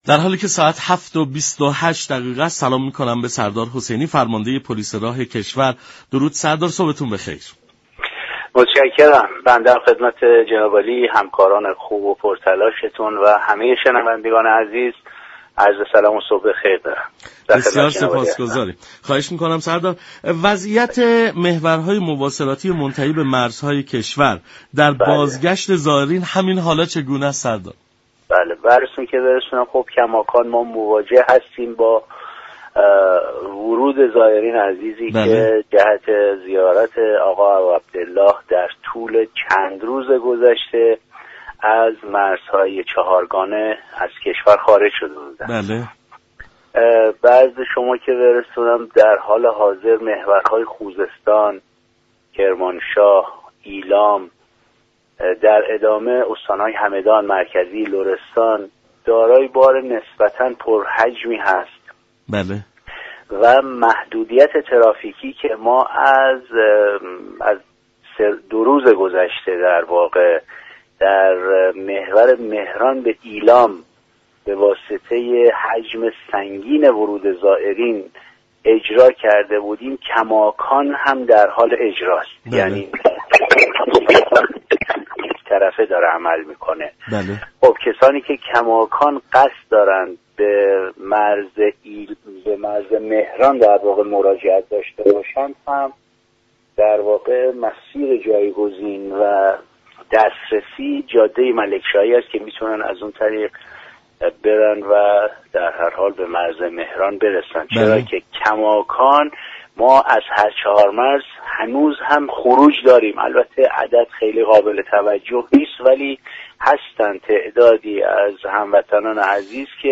فرماندار پلیس راه كشور در گفت و گو با رادیو ایران گفت:كسانی كه می خواهند از طریق مرز مهران به وطن بازگردند می توانند از جاده ملك شاهی وارد مرز مهران شوند.